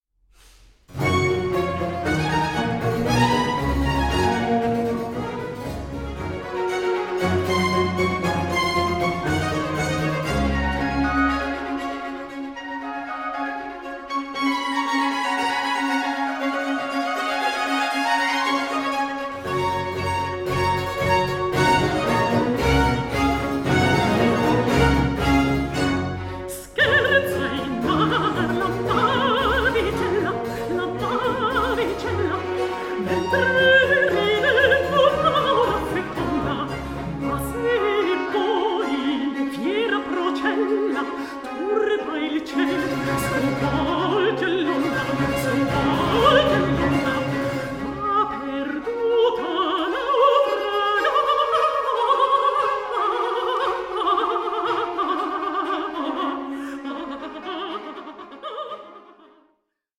Italian baroque ensemble
authentic performances on period instruments
Swedish mezzo-soprano